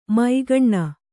♪ māgaṇṇa